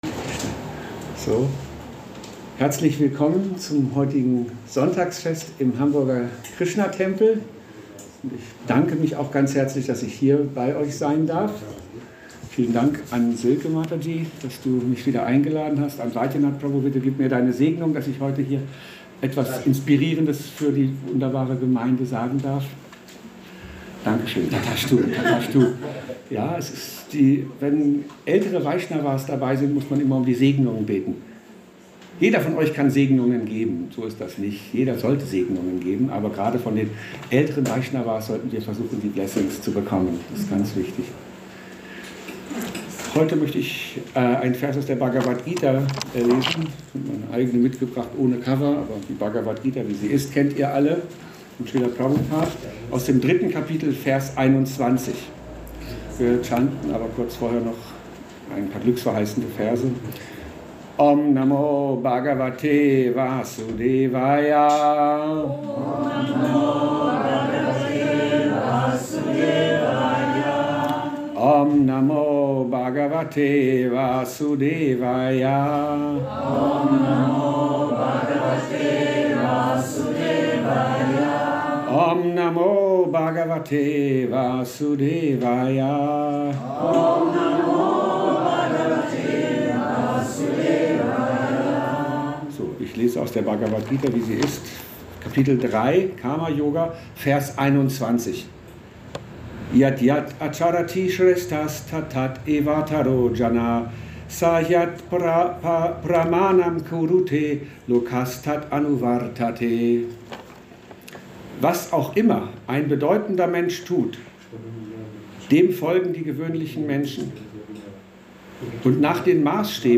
Holy Days - Holy Places - Holy Men – Vortrag